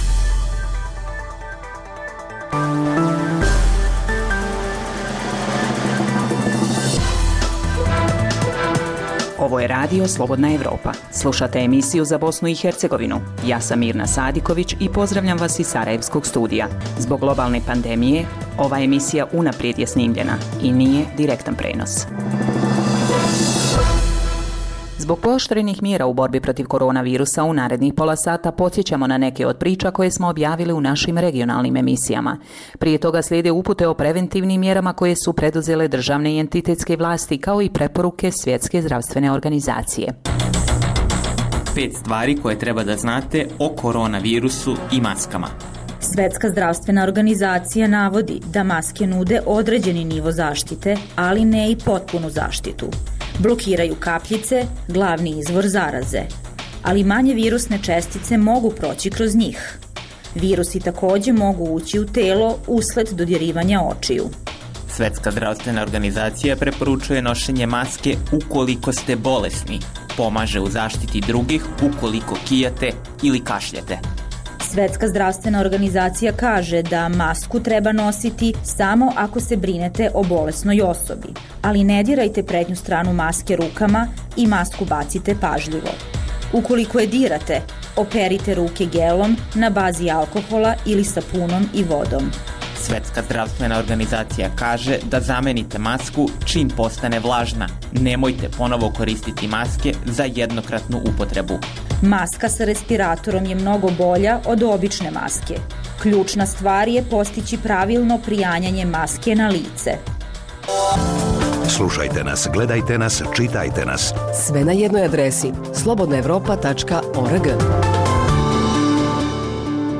Zbog pooštrenih mjera kretanja u cilju sprječavanja zaraze korona virusom, ovaj program je unaprijed snimljen. Poslušajte neke od priča koje smo objavili u regionalnim emisijama